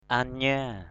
/a-ɲa:/ (d.) thông điệp = message. the message.